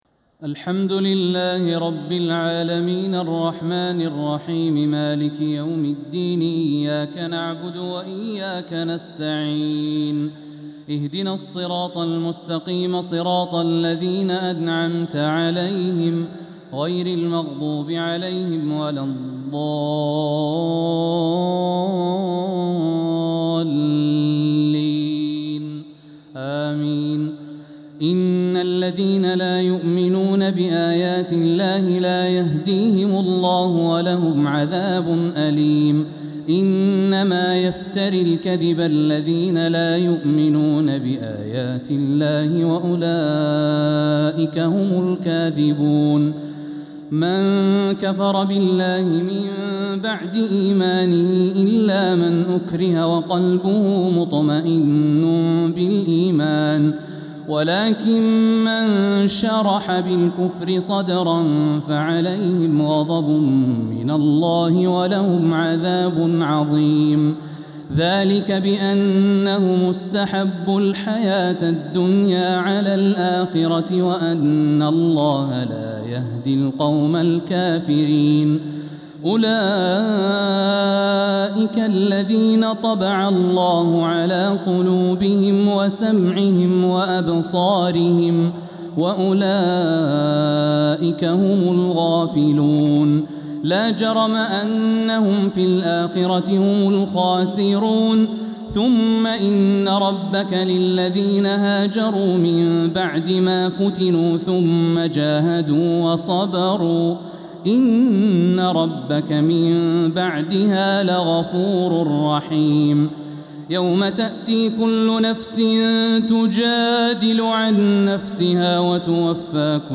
تراويح ليلة 19 رمضان 1447هـ من سورتي النحل (104-128) و الإسراء (1-22) | Taraweeh 19th niqht Surat an-Nahl and Al-Israa 1447H > تراويح الحرم المكي عام 1447 🕋 > التراويح - تلاوات الحرمين